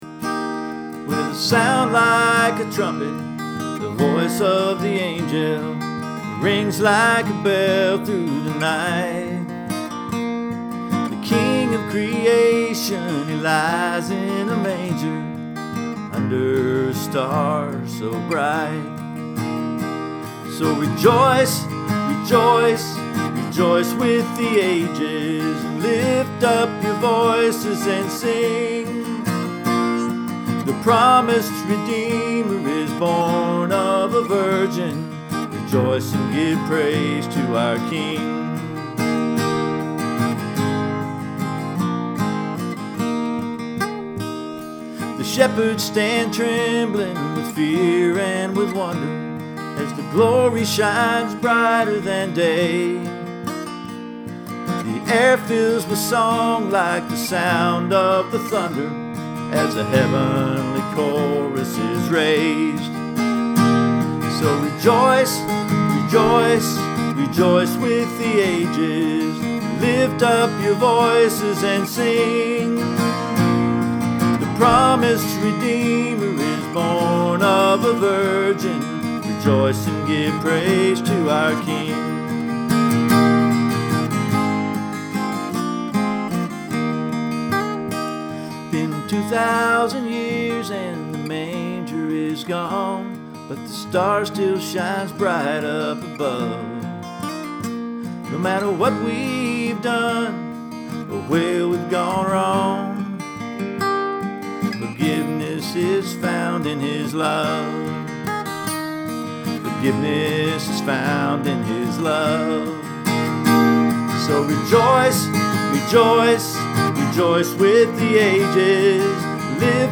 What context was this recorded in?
Here are 3 Christmas songs I have written over the years. These recordings are admittedly lo-fidelity. I made them in Garage Band sitting in my living room with just my guitar and a microphone connected to my laptop.